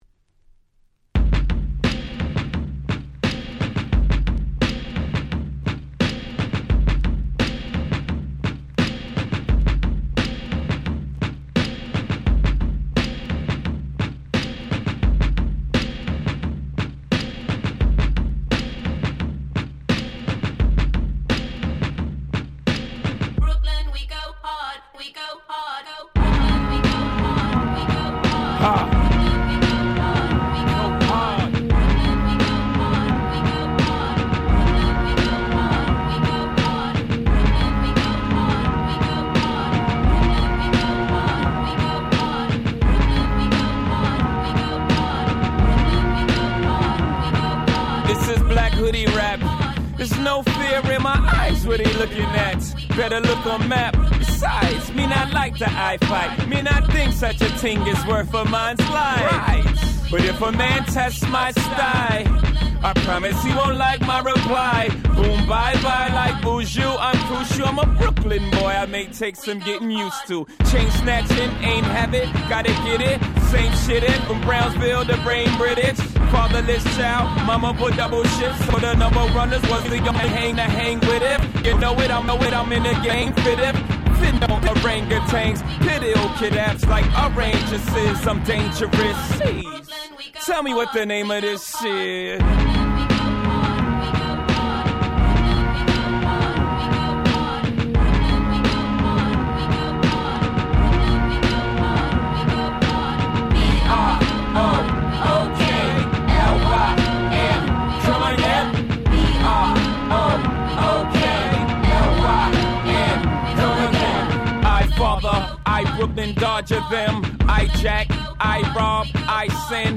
09' Smash Hit Hip Hop !!
このBoom Bap感、90'sファンにも余裕でオススメです！